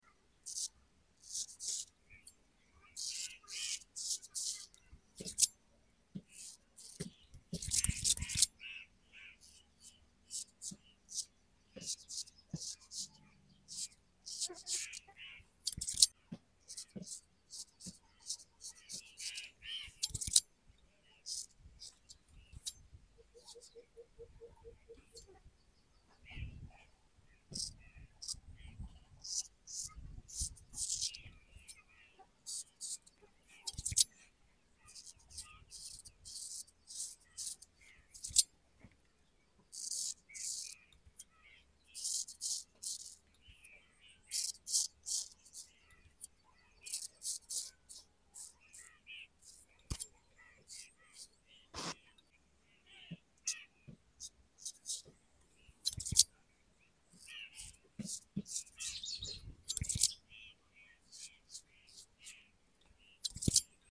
Straight Razor Sounds & Beard sound effects free download
Straight Razor Sounds & Beard Trim | ASMR Shaving